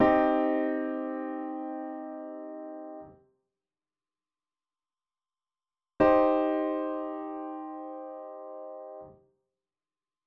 Hearing the quality of a first inversion minor triad
8. Minor triad – Listen in to the quality of the intervals